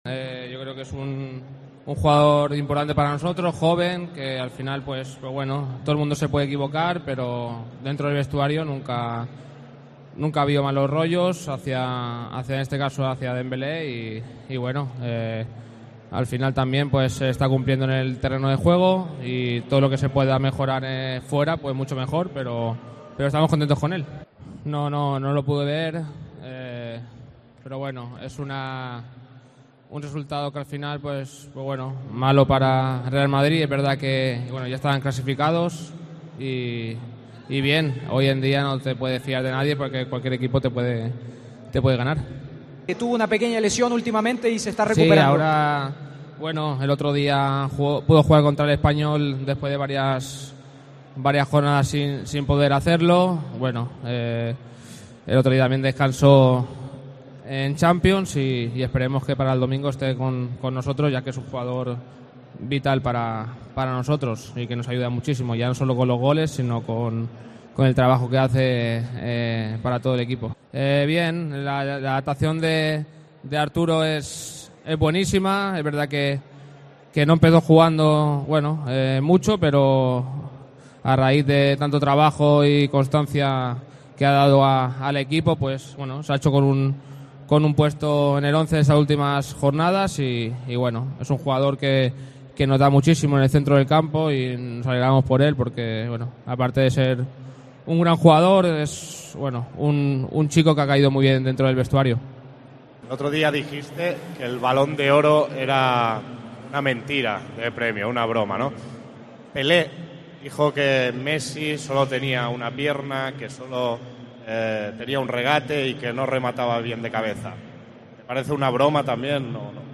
El lateral del Barcelona Jordi Alba analizó la actualidad balugrana en un acto publicitario: